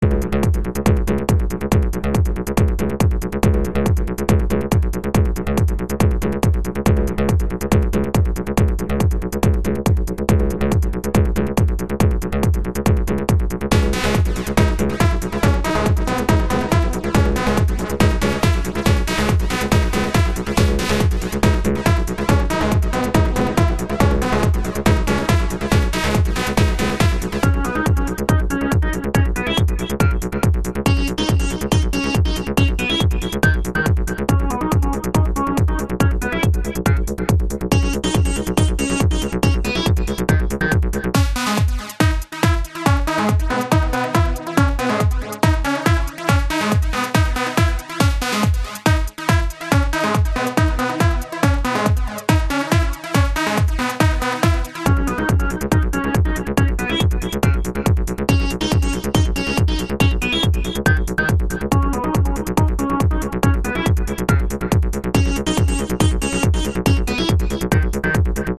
The sound levels of the instruments swift a lot over a short period of time and it's hurting my ears and head, literally, it spoils the song.